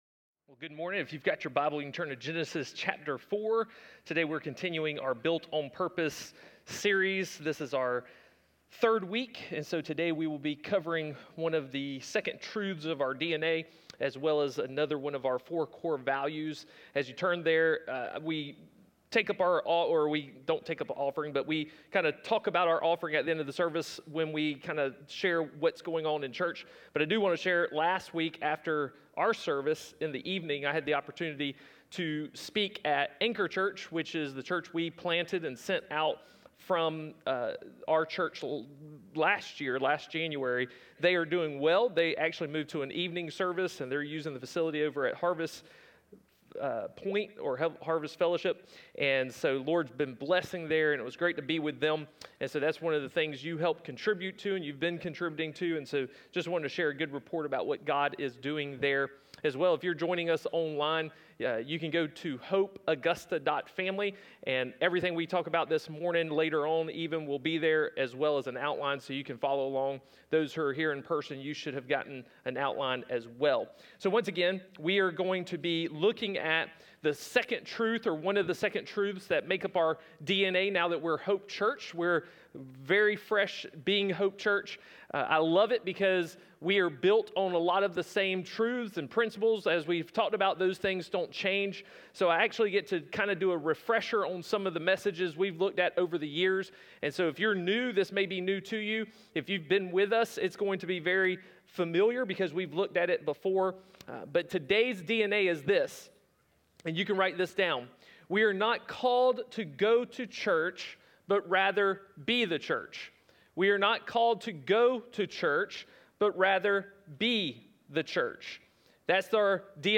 A message from the series "This Is Us." During this series we are exploring who we are as a church and what we are called to be about as followers of Jesus.